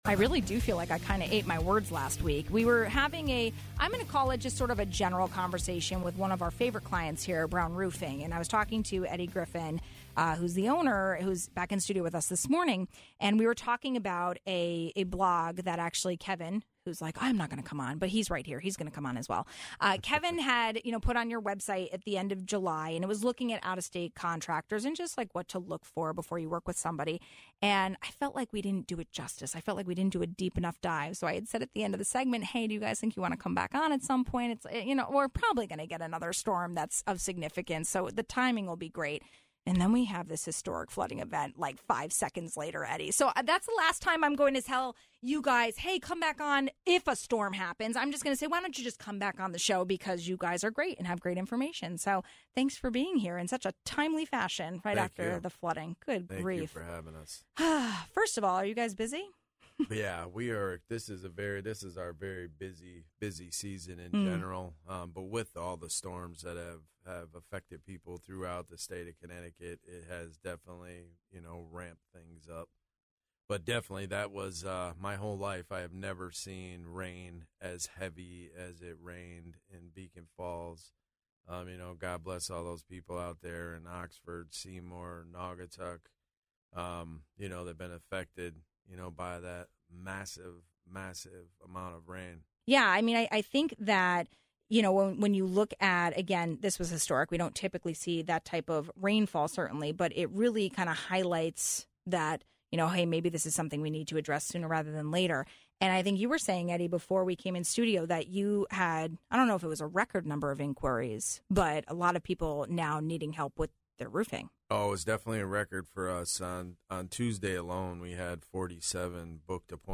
Local roofers say phones are ringing off the hook after the historic flooding event this week in Connecticut. We spoke with Brown Roofing about how to detect scammers or out-of-state roofers if you are paying for roofing repairs.